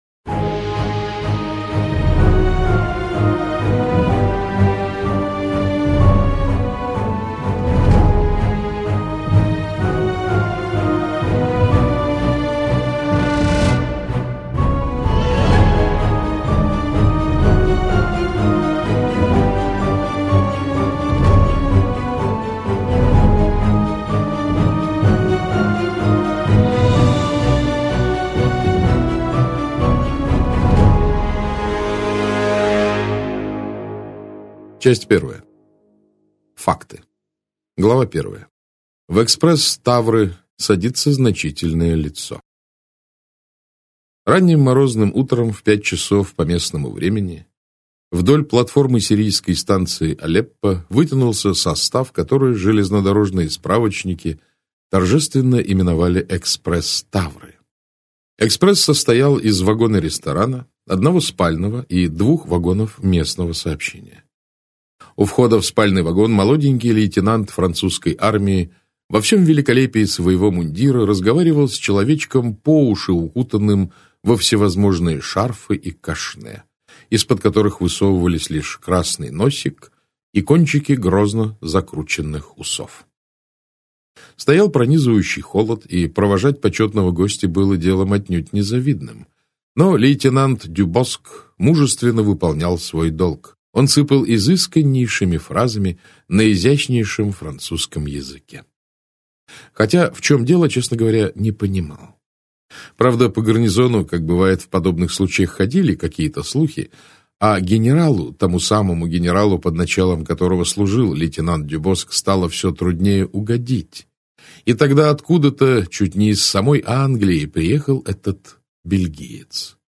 Аудиокнига Убийство в «Восточном экспрессе» - купить, скачать и слушать онлайн | КнигоПоиск